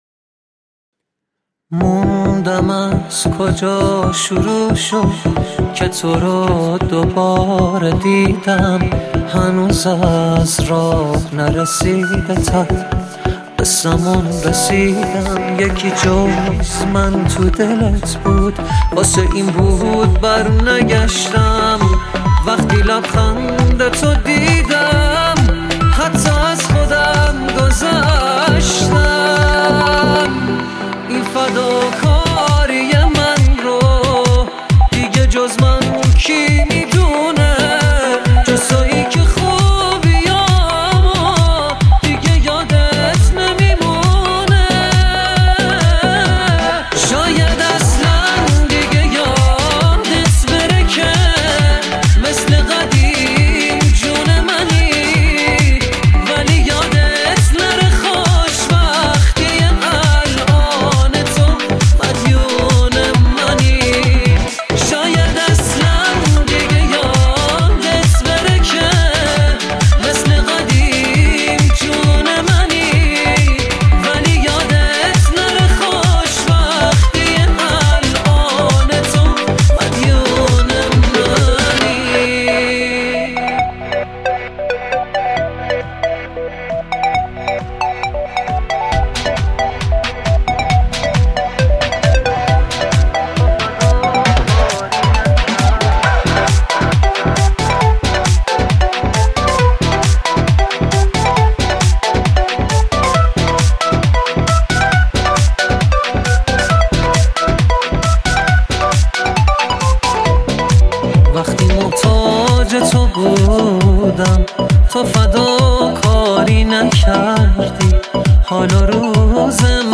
تک آهنگ
پاپ